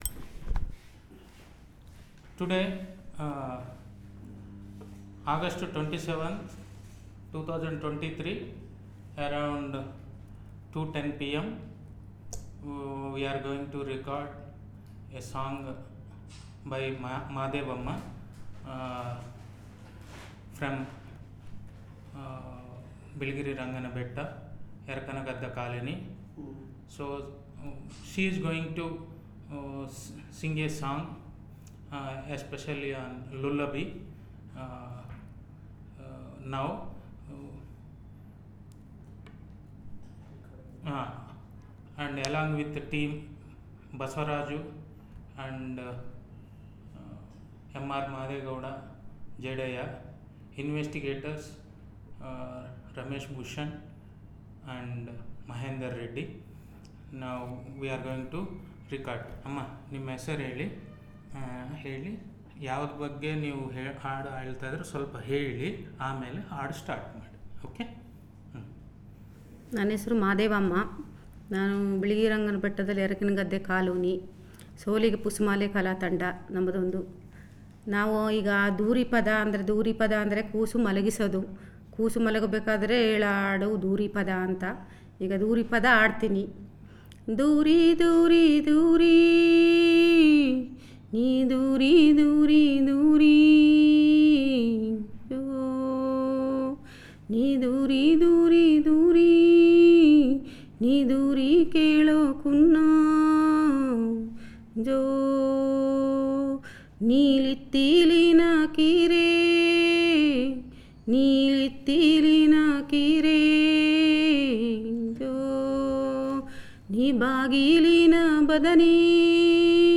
Elicitation of lullaby song